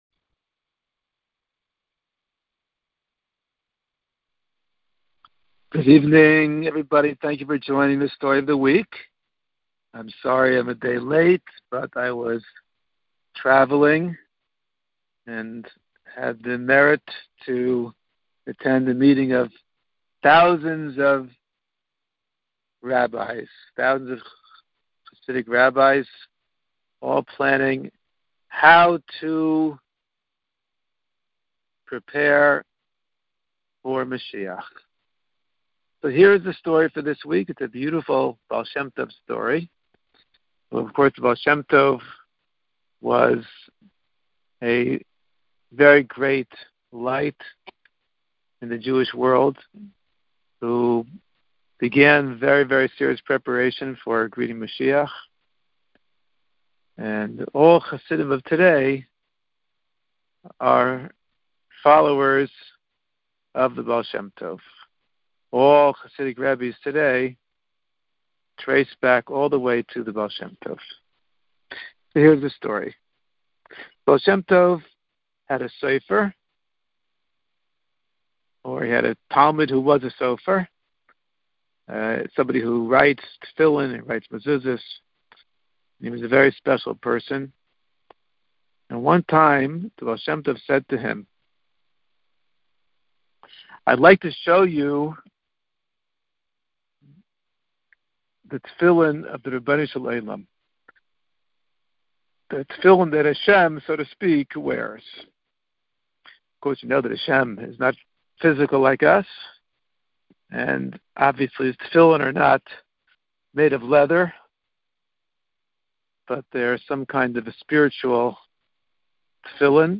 Story time for kids